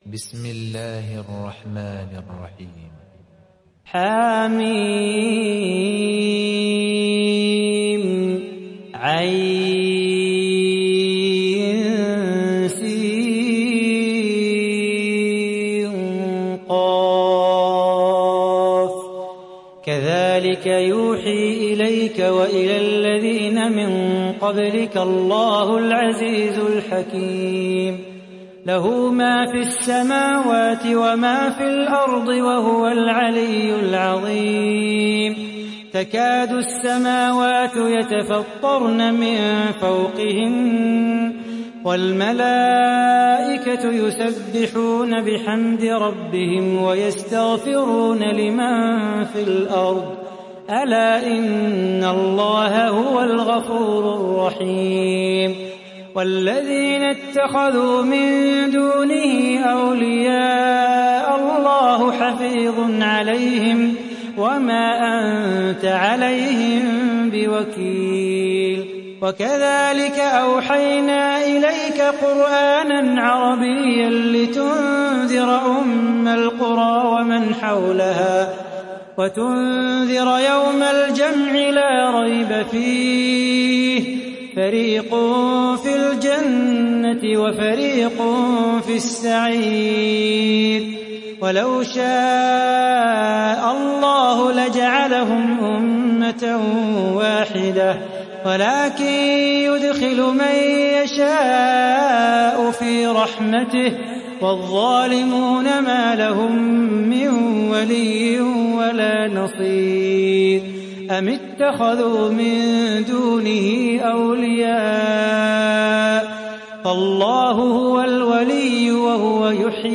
Sourate Ash Shura Télécharger mp3 Salah Bukhatir Riwayat Hafs an Assim, Téléchargez le Coran et écoutez les liens directs complets mp3